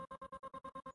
描述：女声样本，音调相当高，用调制的方式做了一个很好的圆润的drone。在MD上录制，用酸进一步处理
标签： 雄蜂 循环 语音
声道立体声